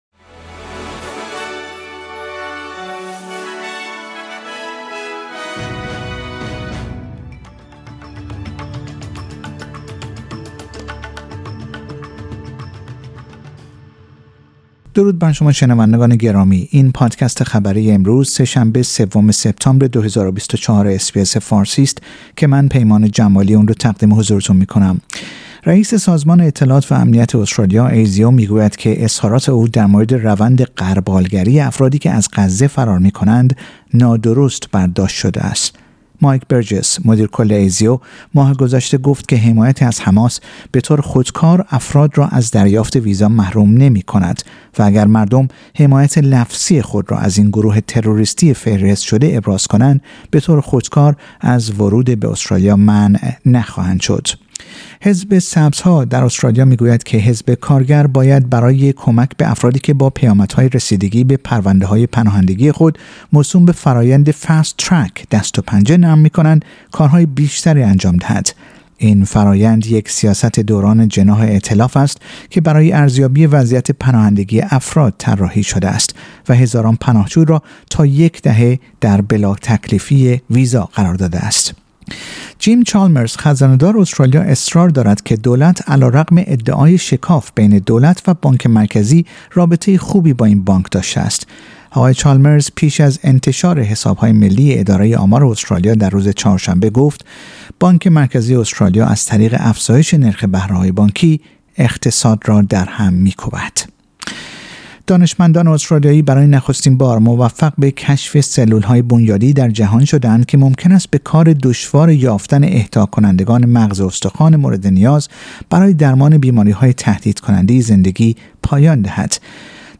در این پادکست خبری مهمترین اخبار استرالیا در روز سه شنبه سوم سپتامبر ۲۰۲۴ ارائه شده است.